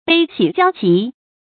注音：ㄅㄟ ㄒㄧˇ ㄐㄧㄠ ㄐㄧˊ
悲喜交集的讀法